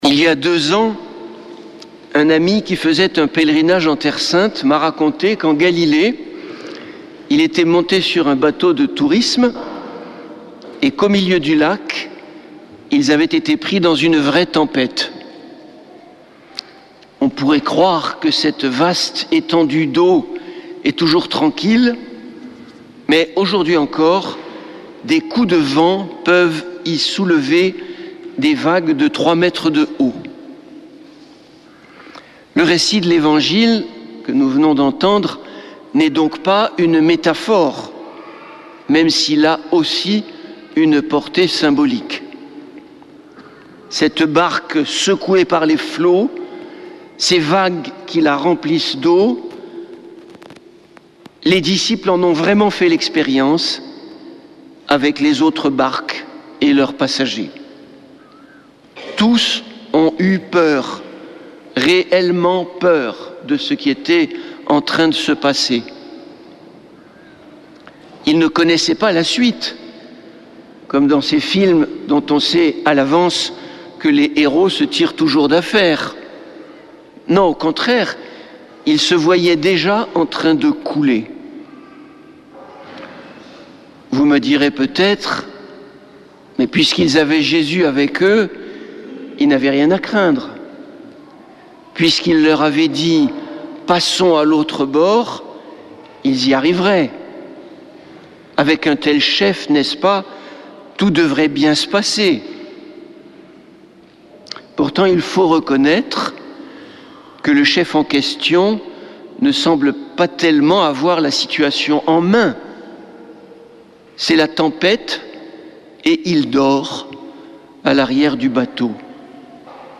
Homélie du 23 juin